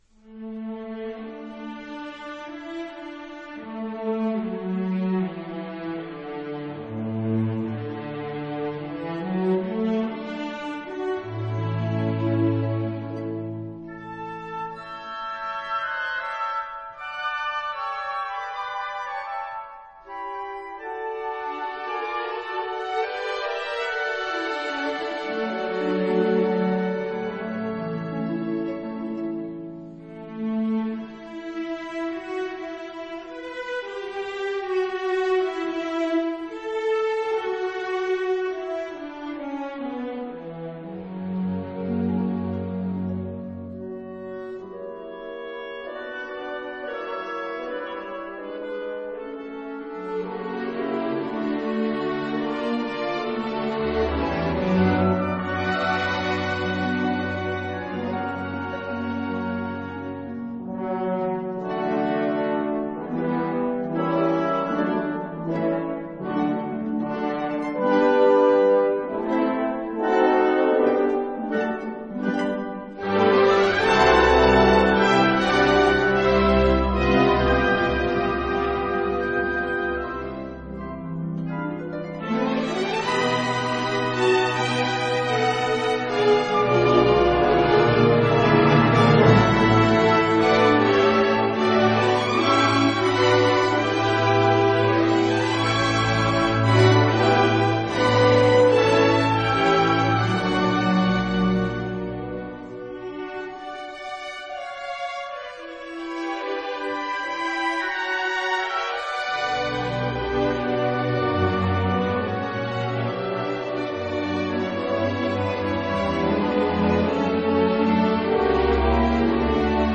是由捷克的楊納傑克愛樂與捷克愛樂合唱團擔綱，
試聽有三段，試聽一是《節慶彌撒》的〈聖哉經〉，